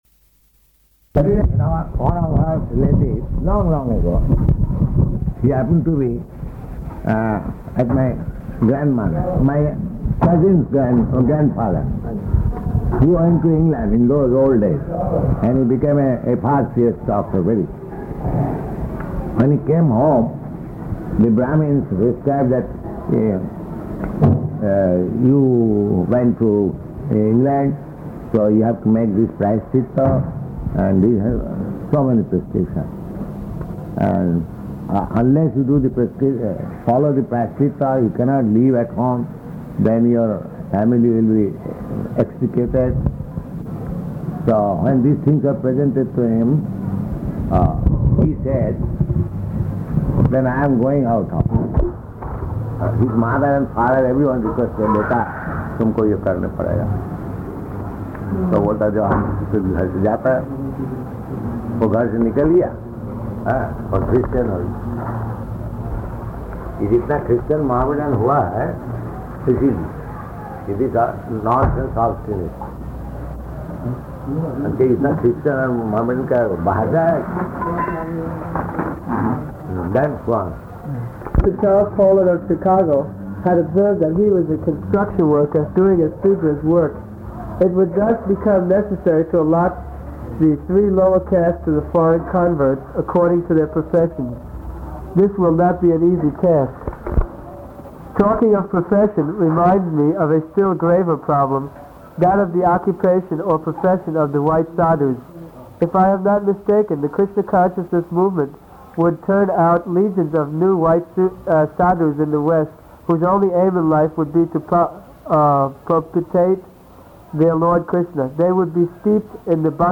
Room Conversation
Type: Conversation
Location: Bombay